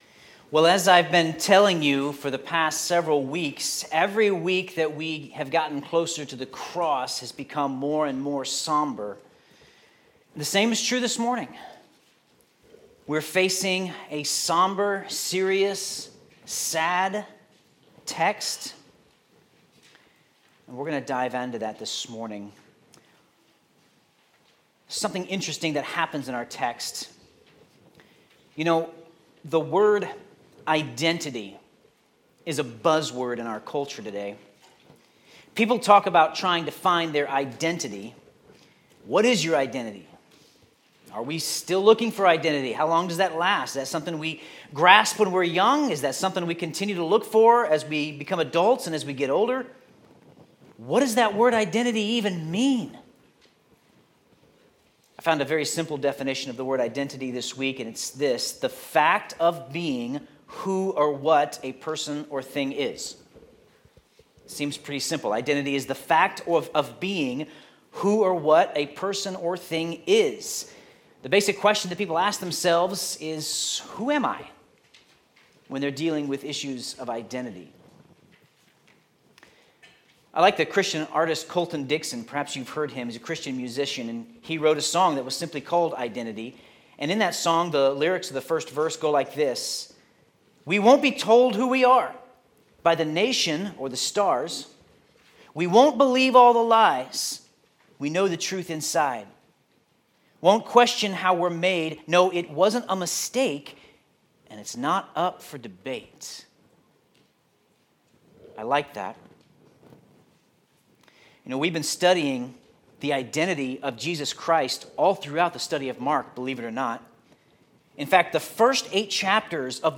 Sermon Notes Jesus is the Son of God.